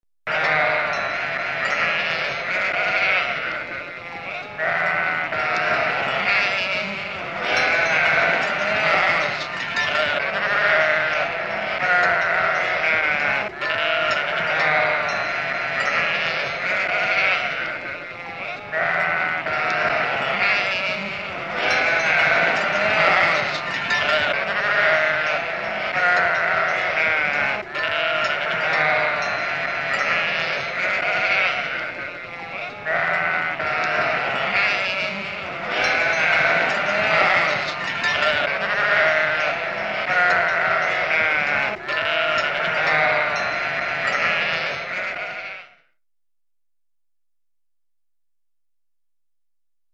Звуки овец, баранов
На этой странице собраны натуральные звуки овец и баранов: от тихого блеяния до громкого крика.
Стадо овец